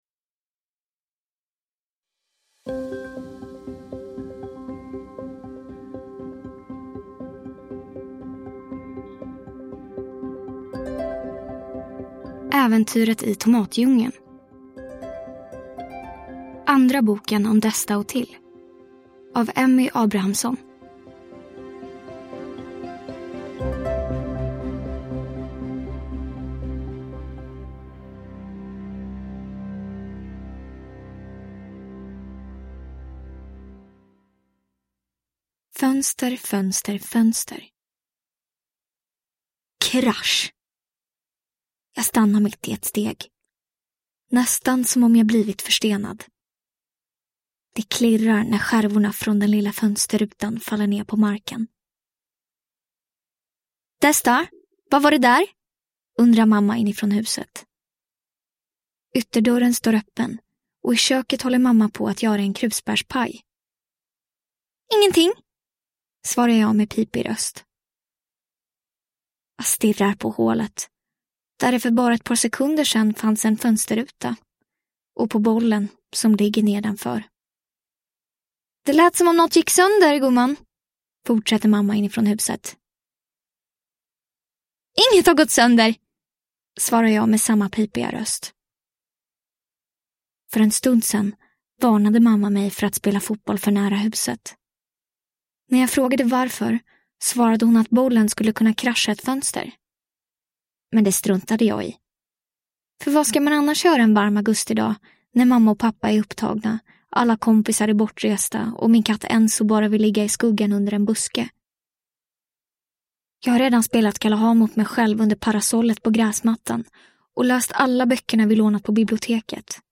Äventyret i tomatdjungeln – Ljudbok